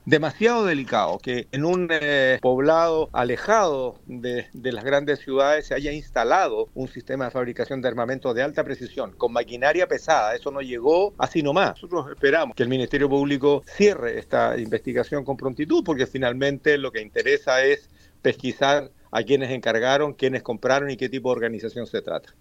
El presidente de la comisión de seguridad del senado y representante de Los Ríos Iván Flores, apuntó a la Fiscalía, esperando que concluya con agilidad las indagatorias.